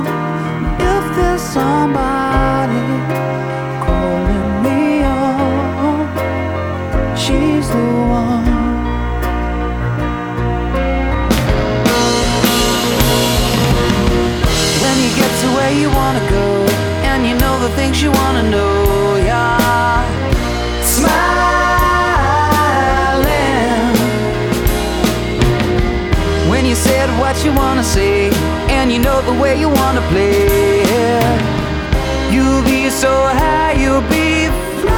Жанр: Поп музыка / Рок / Танцевальные / Альтернатива